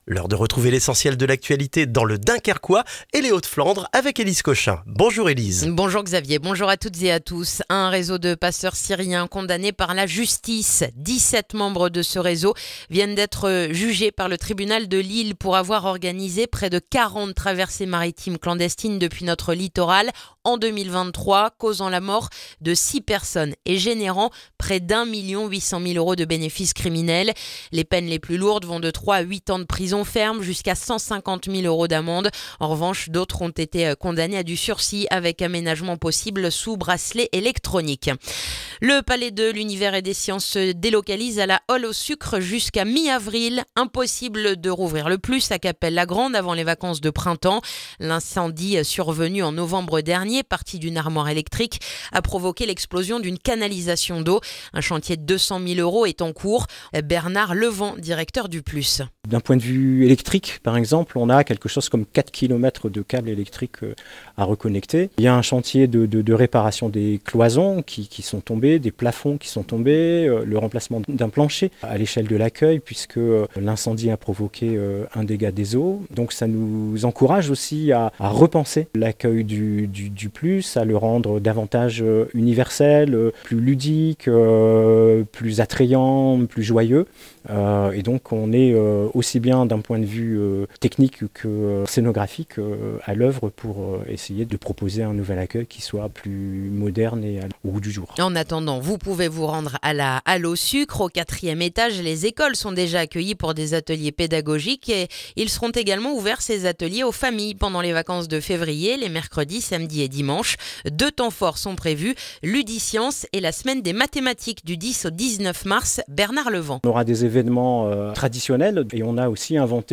Le journal du vendredi 30 janvier dans le dunkerquois